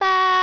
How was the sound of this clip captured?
"baa.ogg" - Ripped from the files of Doki Doki Literature Club!